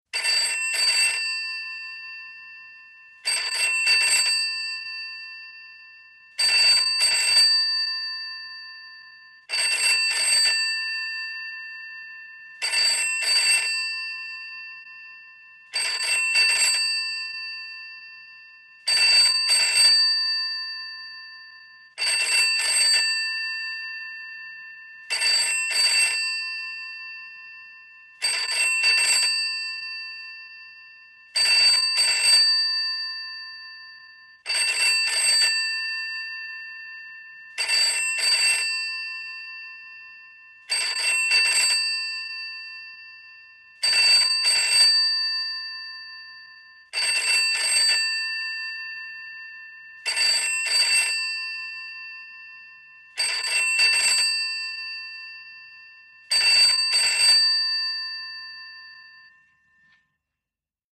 British desk telephone ringing unanswered ( 1960`s )